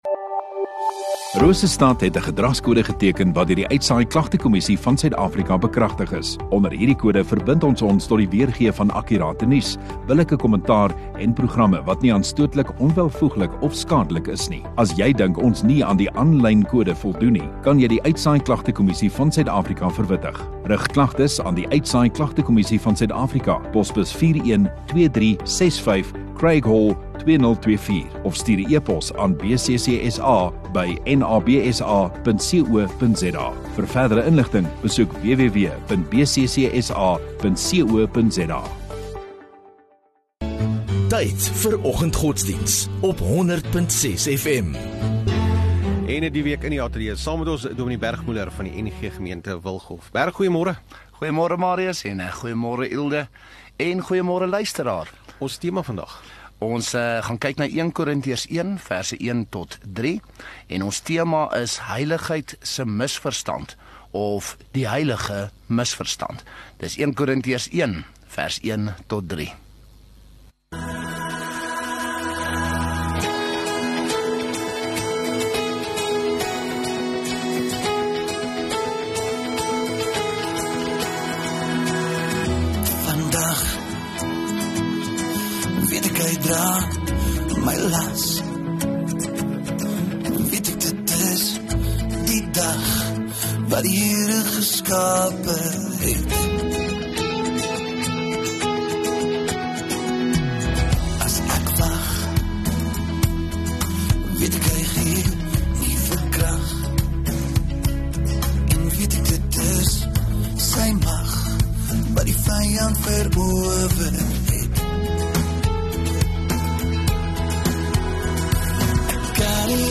17 Sep Dinsdag Oggenddiens